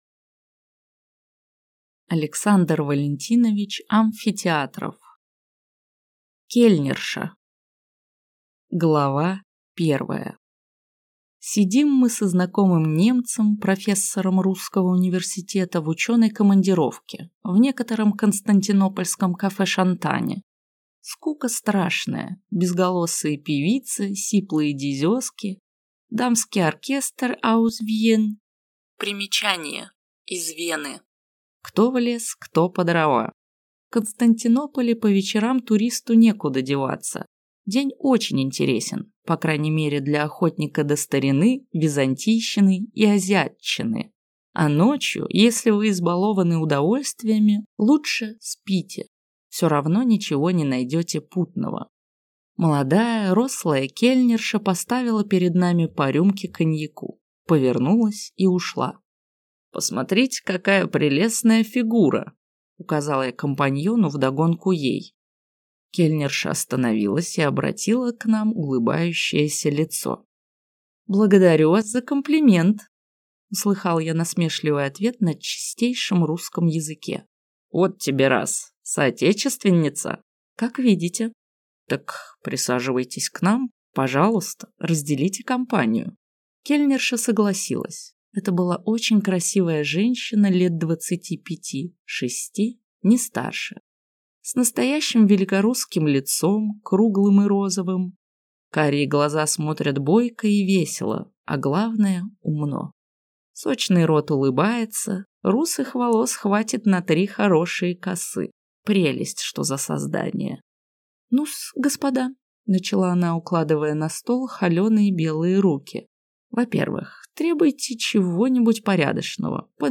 Аудиокнига Кельнерша | Библиотека аудиокниг